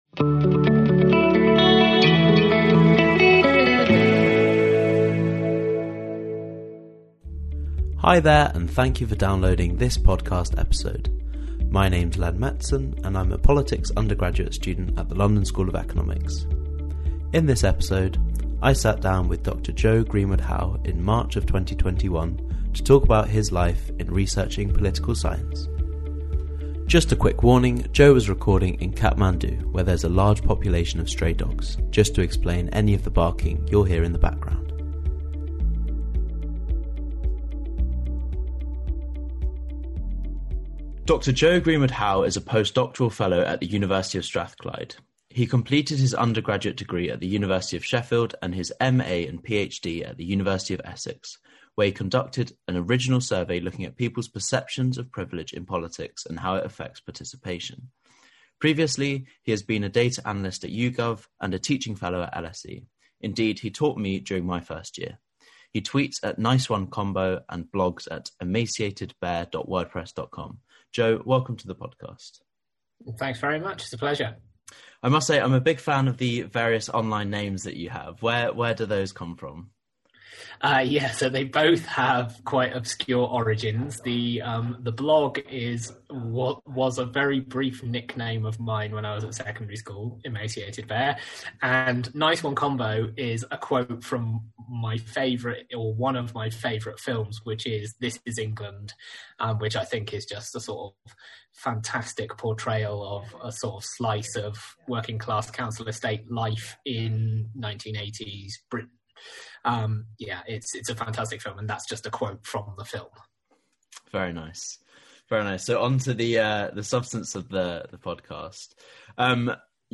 Contemporary British Governance Guest Lecture | Capital, Privilege, and Political Participation